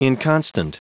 Prononciation du mot inconstant en anglais (fichier audio)
Prononciation du mot : inconstant